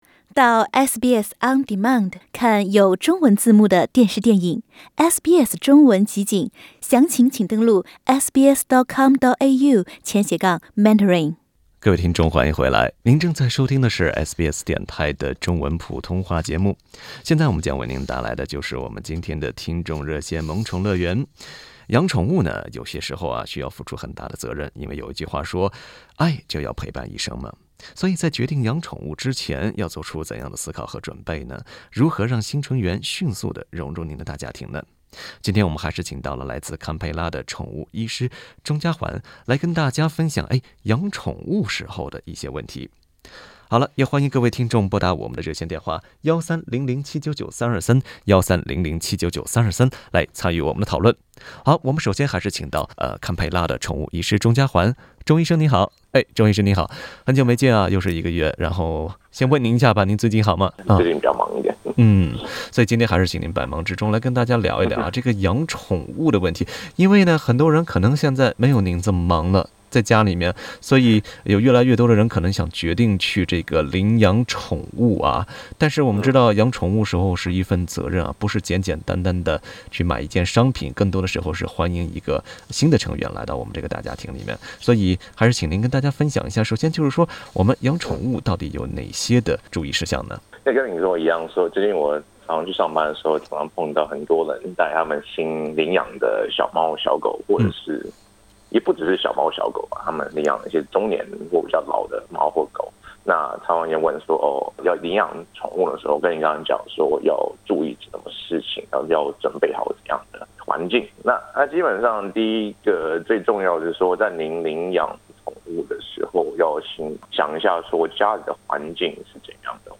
Source: Getty 一些听众也提出了一些常见的问题。